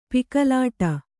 ♪ pikalāṭa